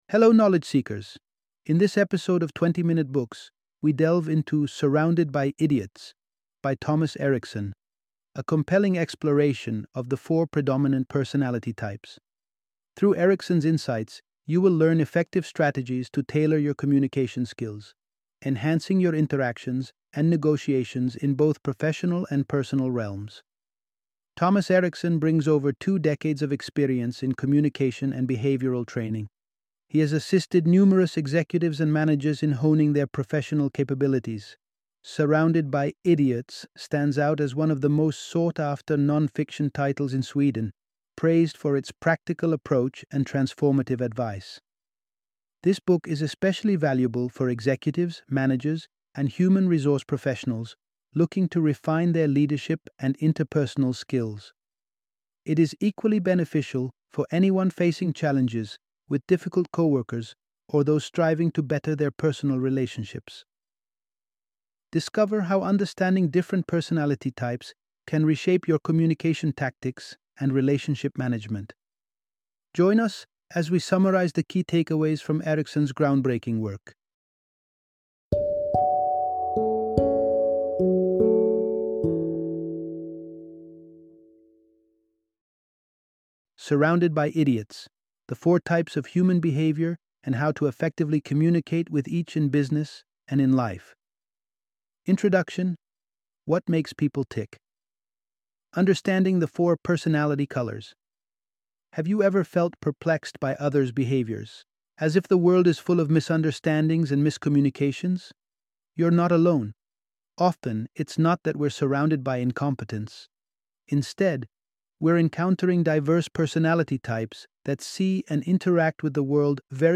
Surrounded by Idiots - Audiobook Summary